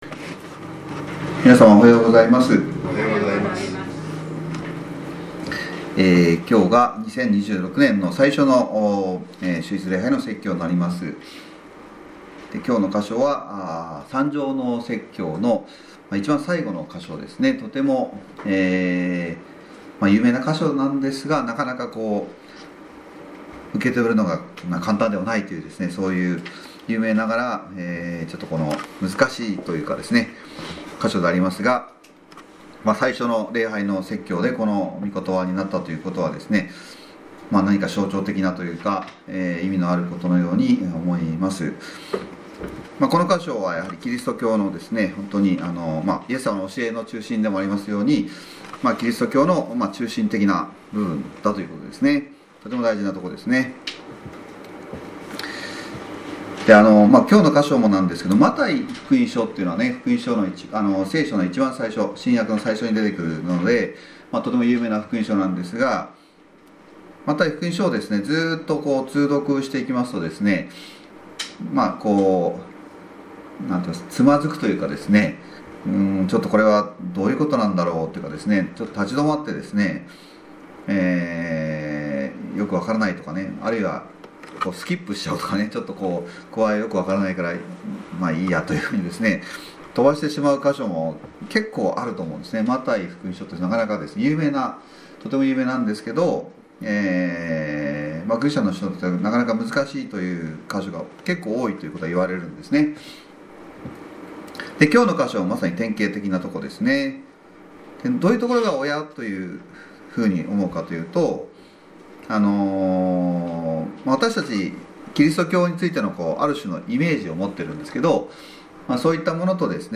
今日が2026年の最初の説教となりますが、この箇所を今年の初めの説教として与えられたことは、何か象徴的なといいますか、大変大きな意味があることであるように思います。この箇所はキリスト教の核心的な部分に係ることだからです。